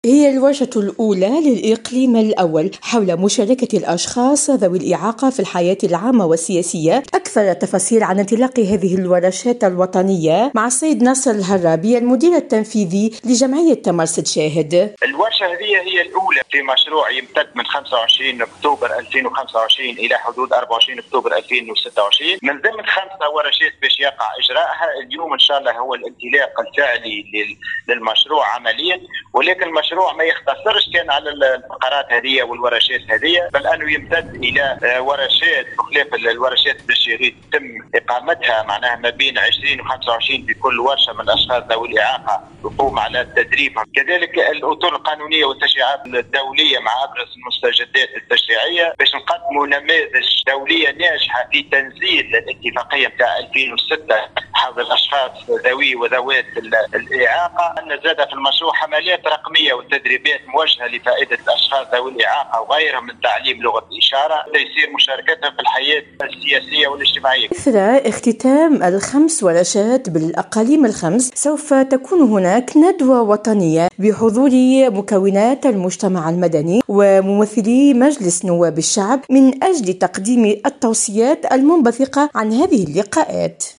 خبر سماعي ..